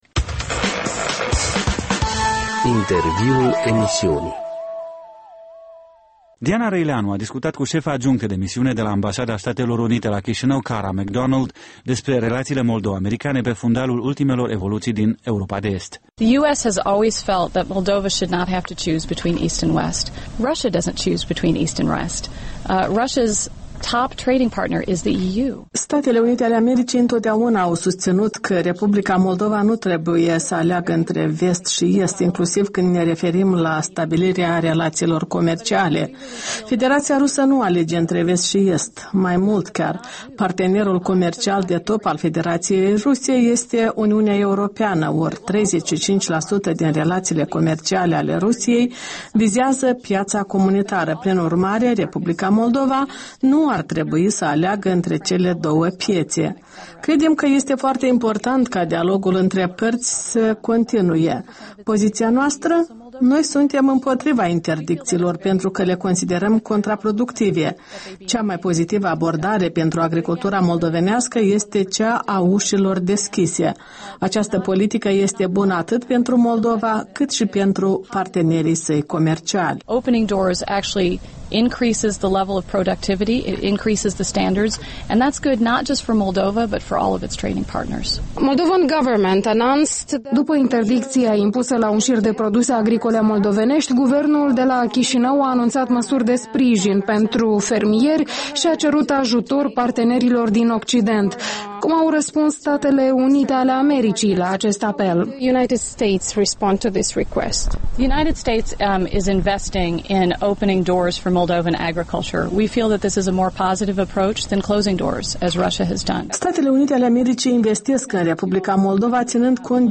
Un interviu cu diplomata americană Kara McDonald